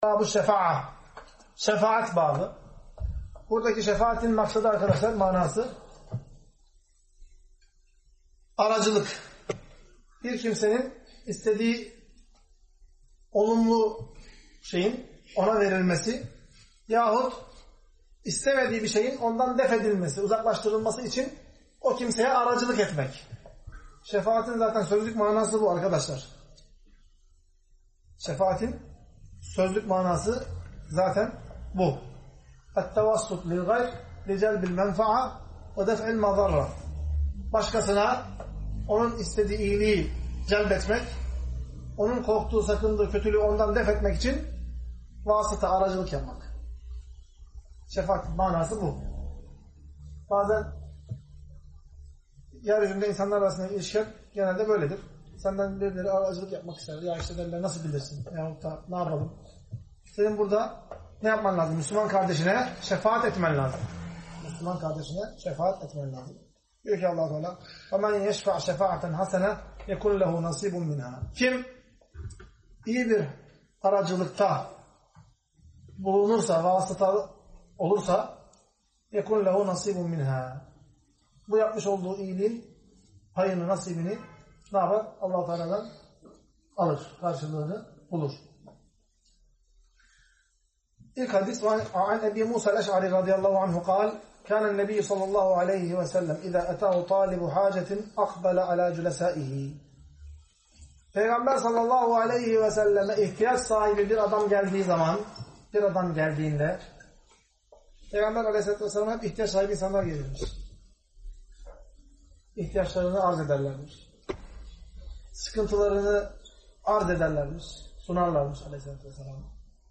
Ders - 30. Bölüm - Şefaat ( iyi işlere Aracılık Etmek )